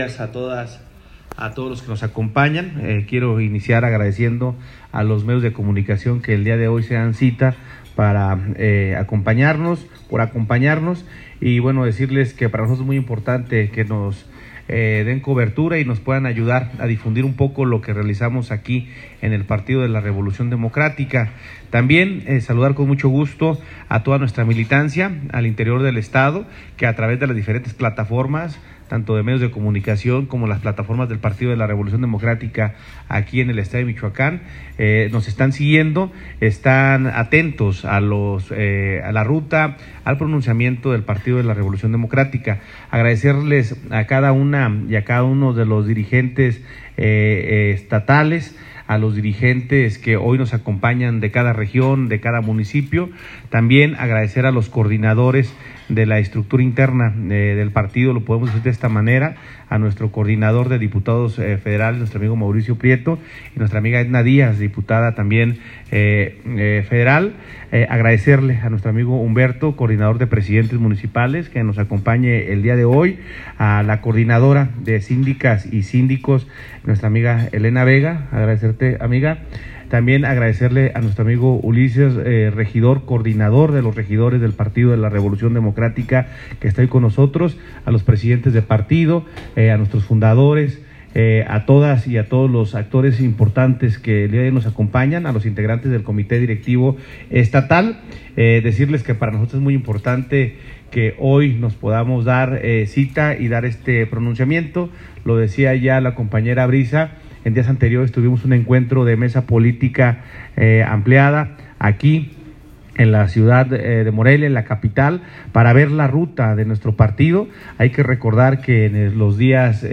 Diputado Federal, Mauricio Prieto Gómez, Coordinador de Diputados Federales perredistas de Michoacán
Ahora escucharán la intervención del Presidente Municipal de Huandacareo, Humberto González, Coordinador de Alcaldes y Alcaldesas perredistas de Michoacán: